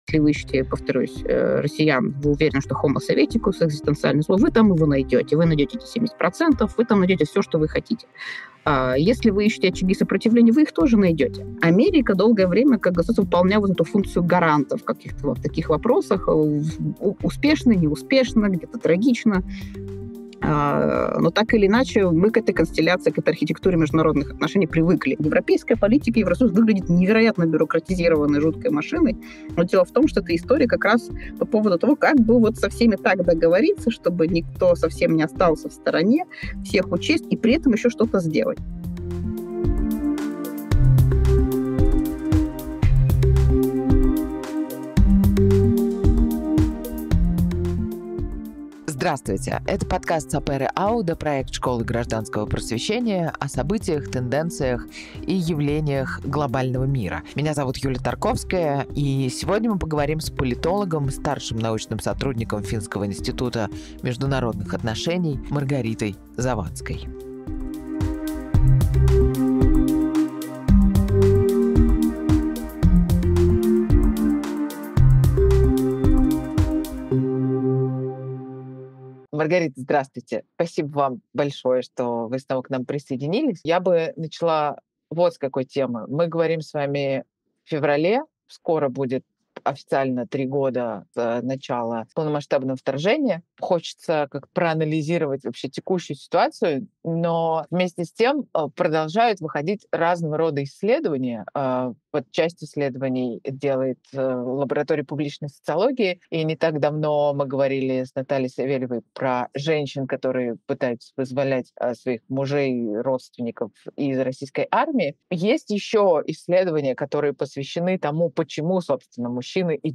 журналистка
политолог